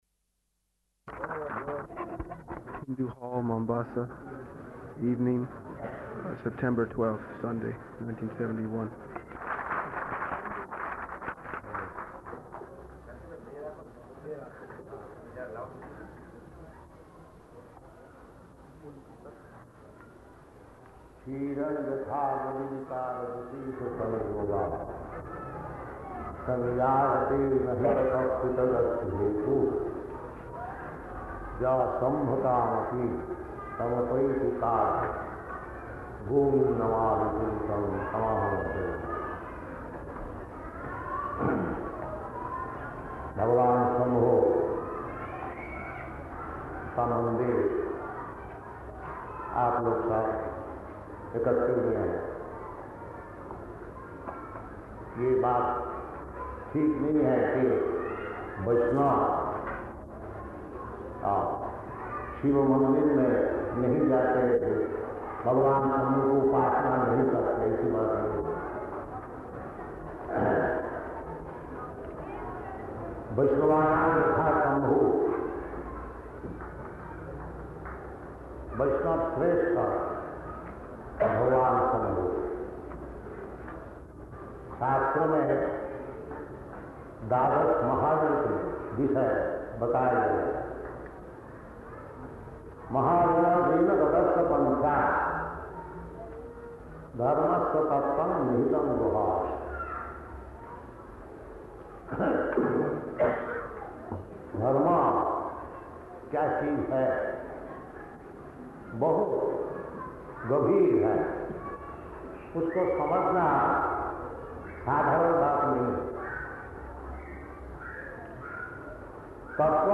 Lecture in Hindi
Lecture in Hindi --:-- --:-- Type: Lectures and Addresses Dated: September 12th 1971 Location: Mombasa Audio file: 710912LE-MOMBASA.mp3 Devotee: City Hall Mombasa, evening September 12th.